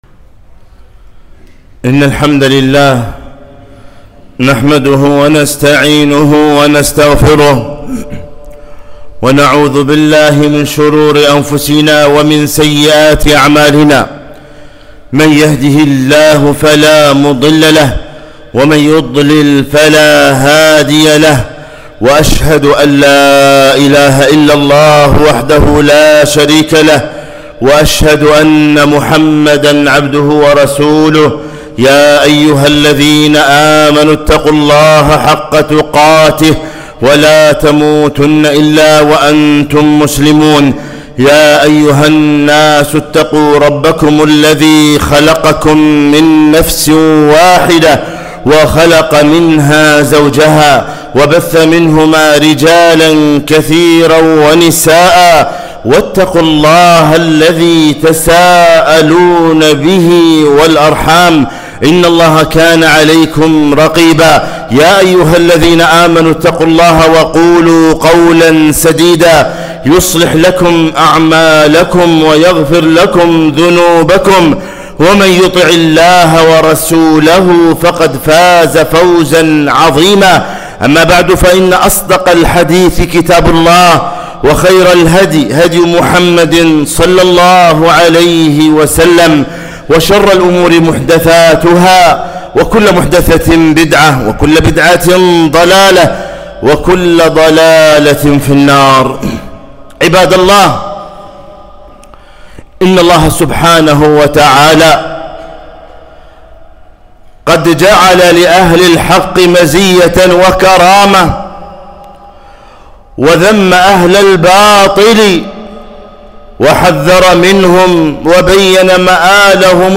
خطبة - فطوبى للغرباء